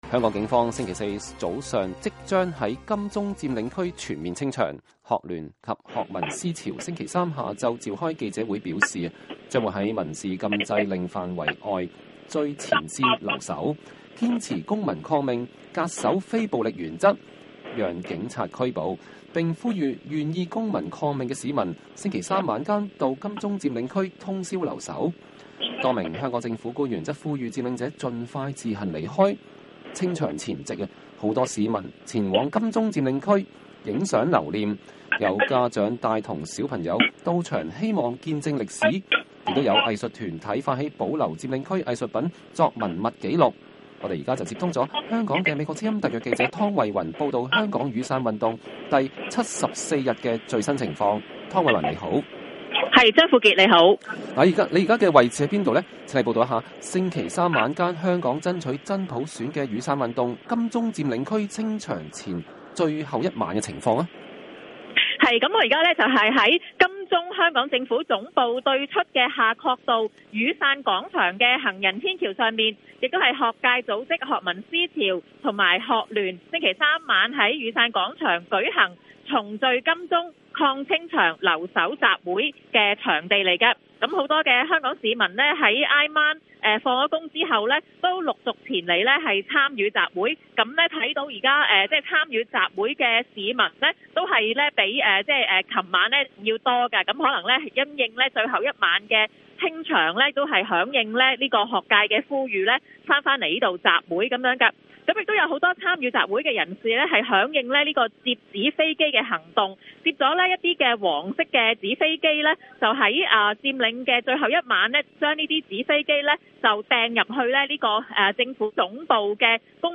現場連線：香港金鐘清場前 市民拍照見證歷史